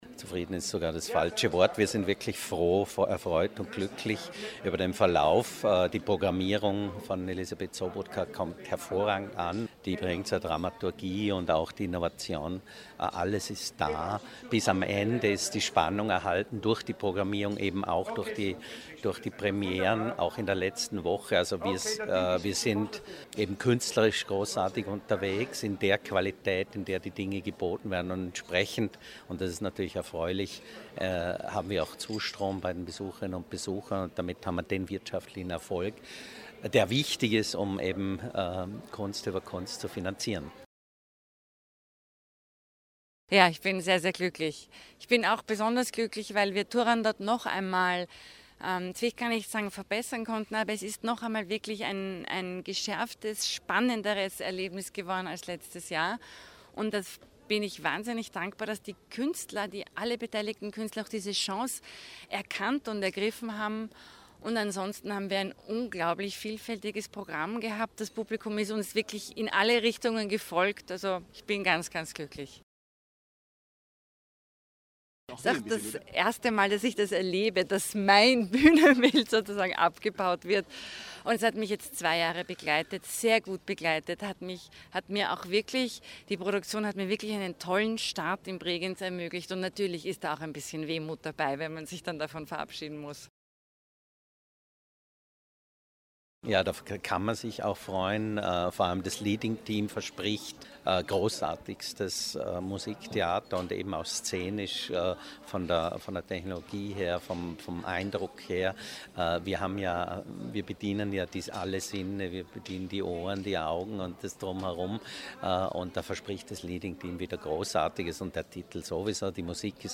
O-Ton Service Pressekonferenz - vorläufige Bilanz